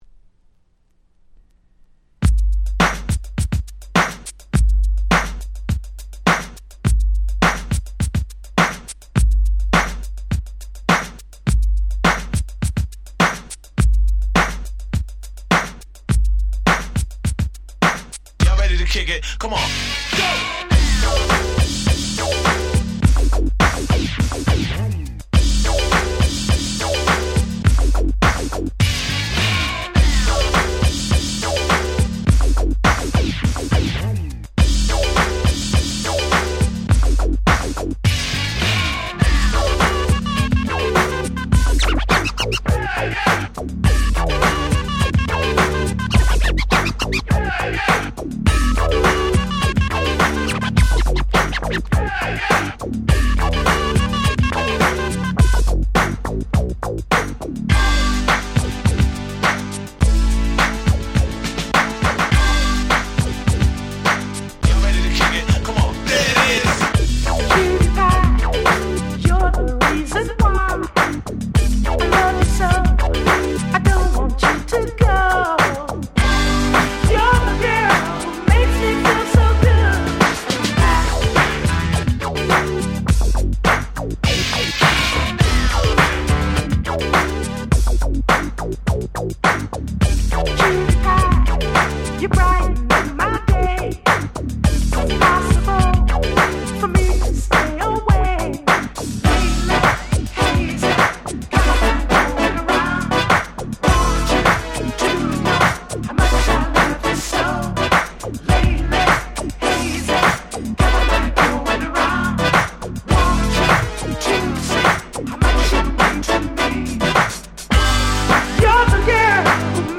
自分が当時好んで使用していた曲を試聴ファイルとして録音しておきました。